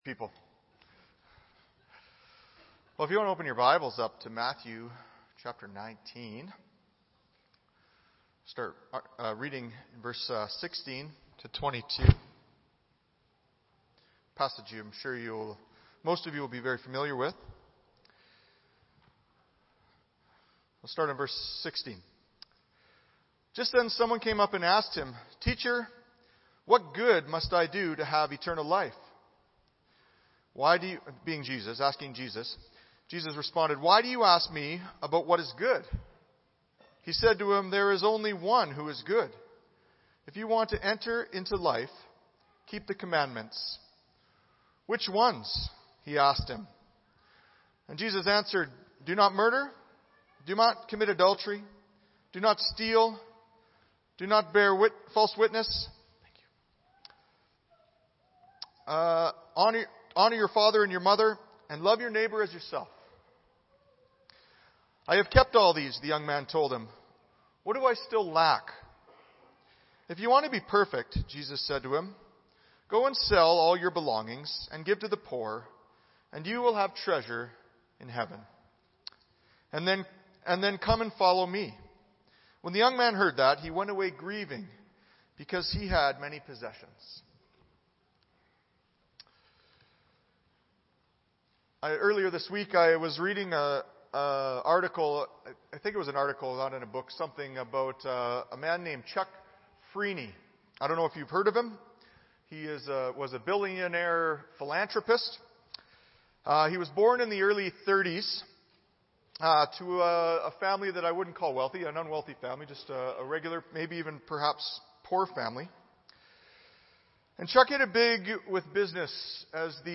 Audio Sermon Library Is He Talking to Me?